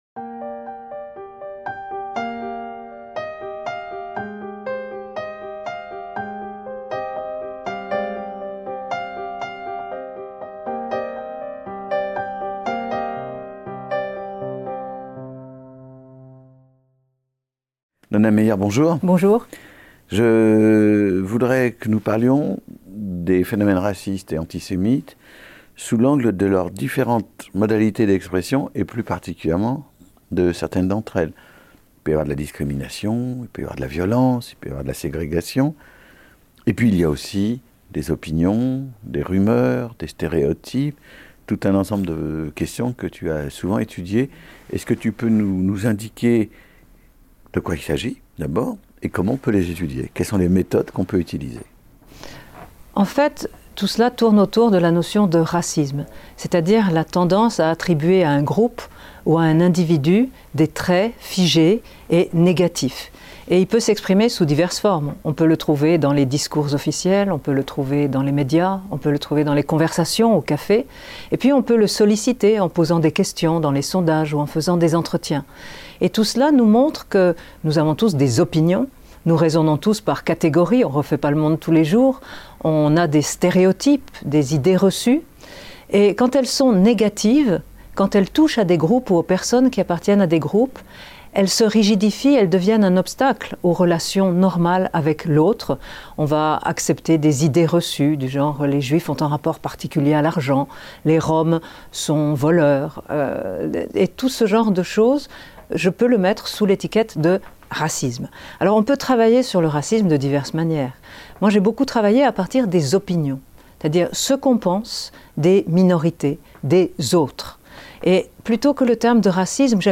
Opinions, préjugés et stéréotypes - Un entretien avec Nonna Mayer | Canal U